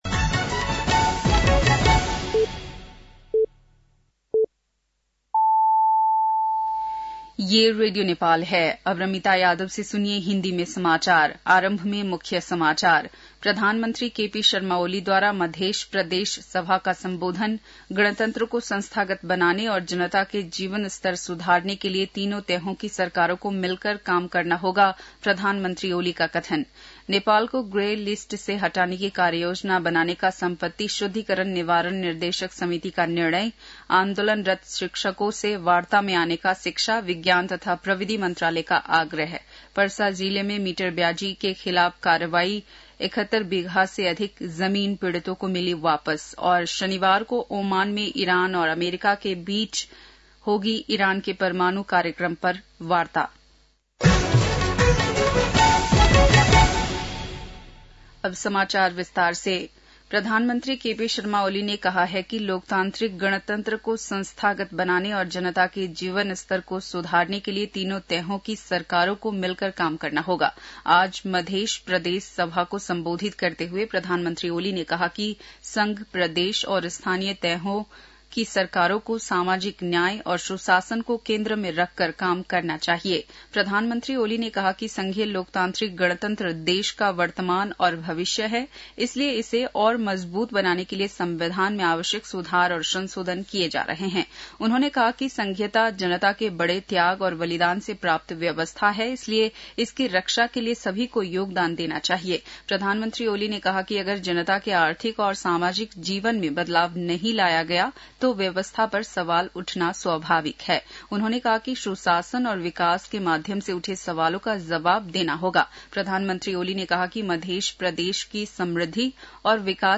बेलुकी १० बजेको हिन्दी समाचार : २६ चैत , २०८१